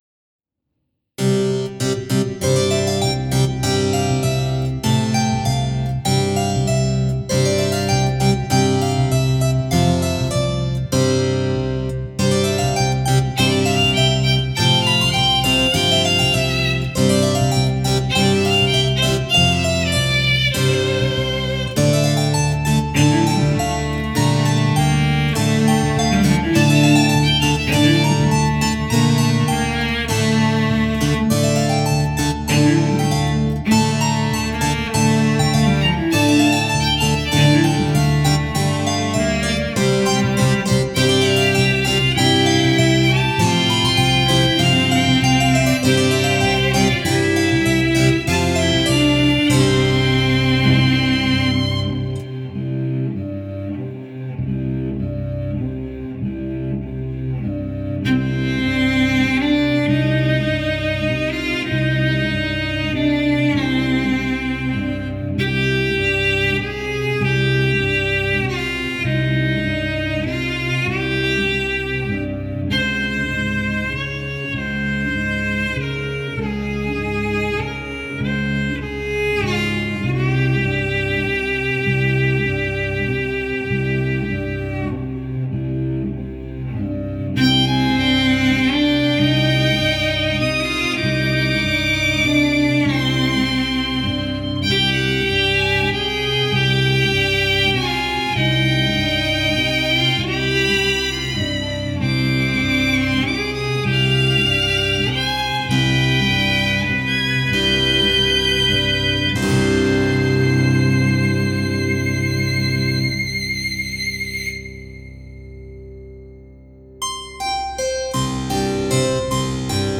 Danse macabre — a dancing skeleton
Sampler: Kontakt 3
Cembalo: Personal Homunculus
Violine: Garritan Solo Stradivarius
Violoncelli: Garritan Gofriller Cello
Bassgitarre: Scarbee Black Bass